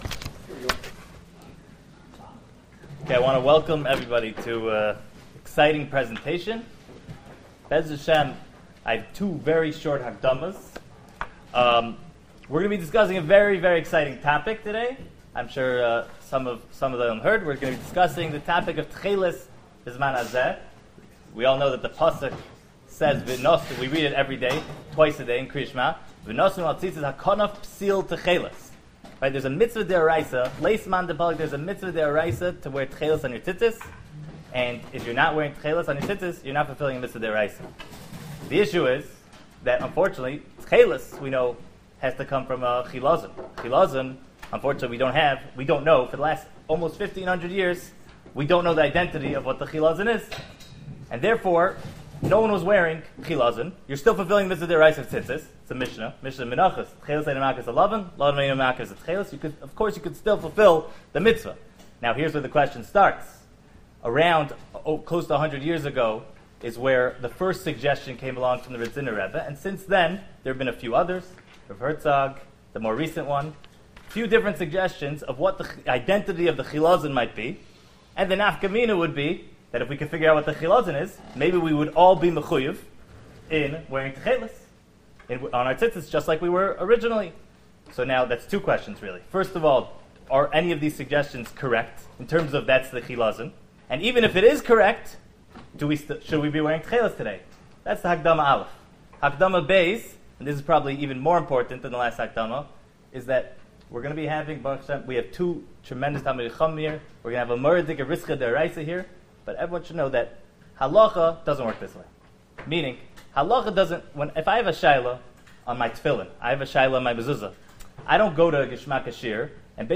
Halachik Debate on the topic of Techeiles
Shiur recorded in Yeshivas Ohr Reuven, Monsey, NY.